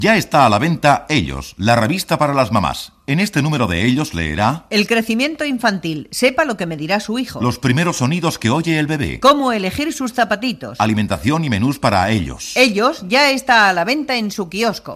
Gènere radiofònic Publicitat